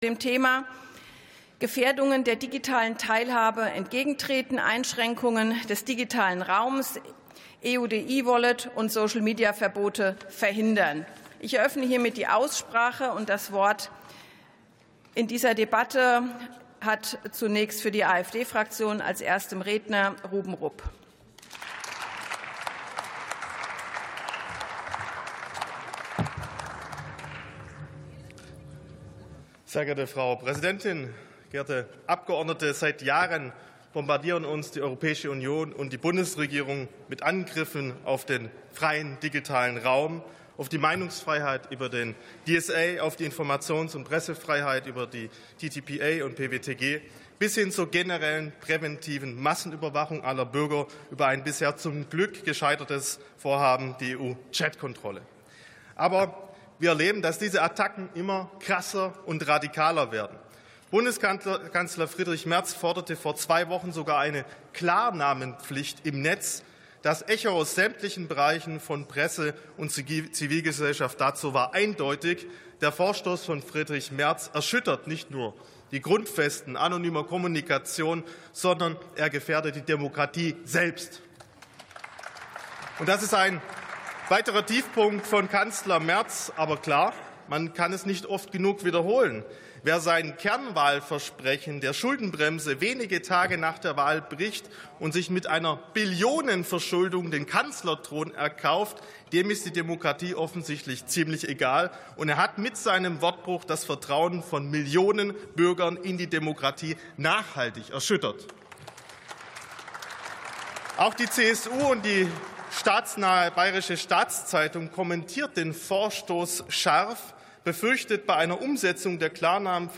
62. Sitzung vom 05.03.2026. TOP ZP 12: Aktuelle Stunde: Gefährdungen der digitalen Teilhabe ~ Plenarsitzungen - Audio Podcasts Podcast